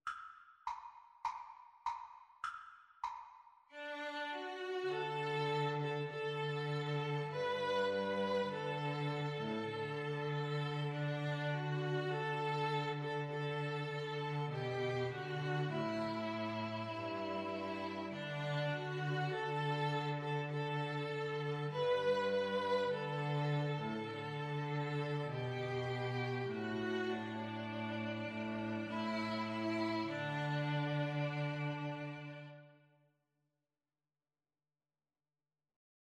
Free Sheet music for String trio
D major (Sounding Pitch) (View more D major Music for String trio )
Cantabile =c.100
4/4 (View more 4/4 Music)